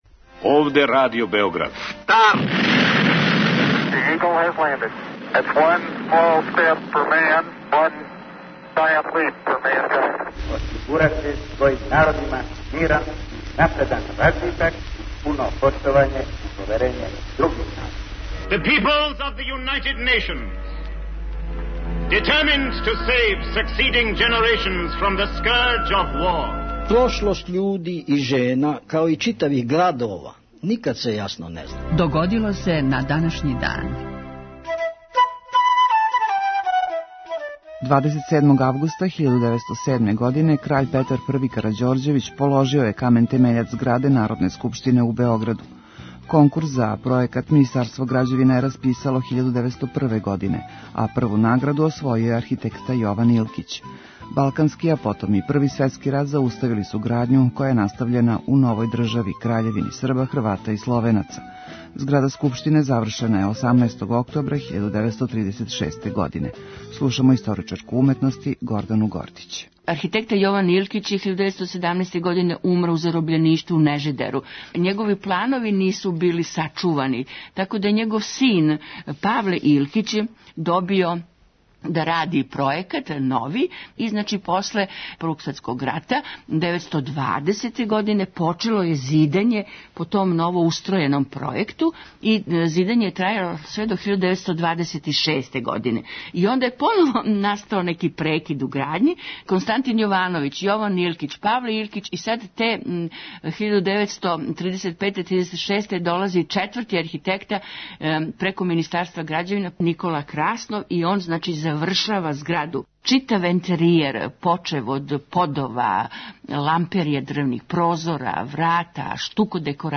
Емисија Догодило се на данашњи дан, једна од најстаријих емисија Радио Београда свакодневни је подсетник на људе и догађаје из наше и светске историје. У 5-томинутном прегледу, враћамо се у прошлост и слушамо гласове људи из других епоха.